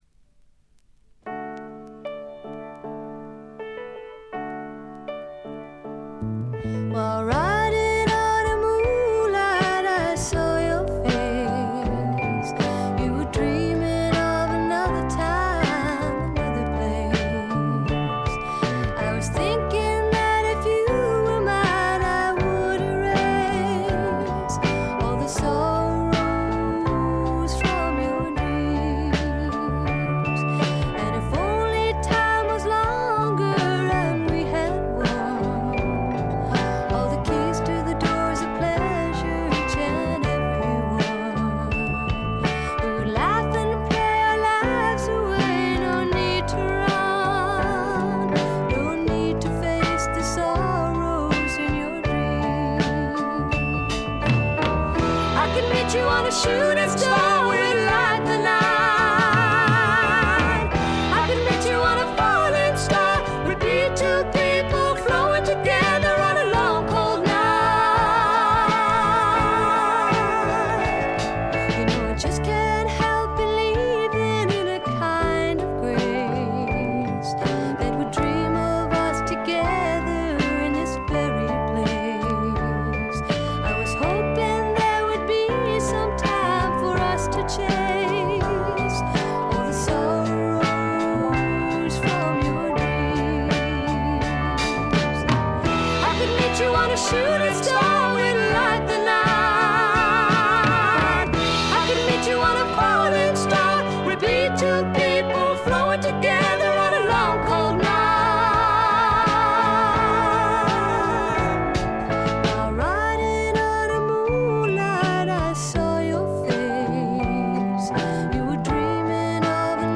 ホーム > レコード：英国 SSW / フォークロック
静音部でチリプチが聞かれますが気になるノイズはありません。
試聴曲は現品からの取り込み音源です。